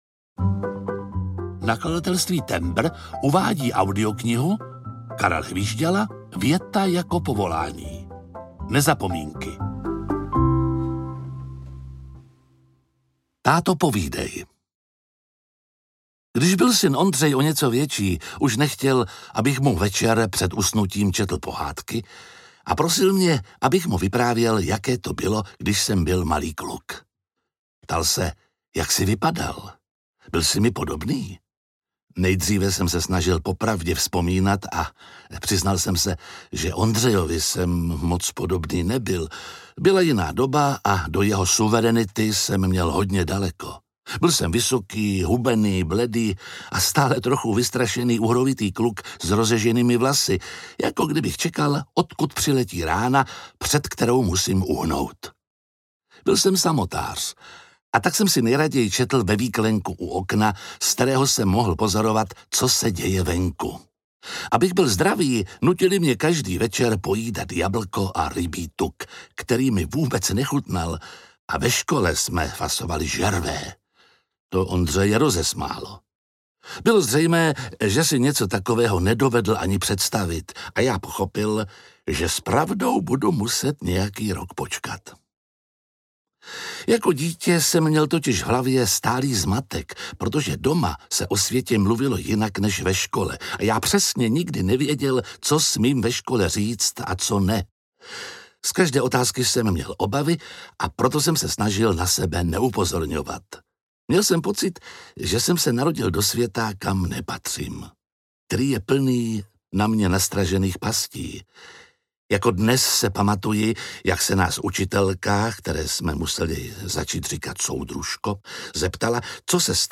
Věta jako povolání – Nezapomínky audiokniha
Ukázka z knihy
To nejlepší ze souboru čte autorův dobrý přítel Jiří Lábus.
• InterpretJiří Lábus, Karel Hvížďala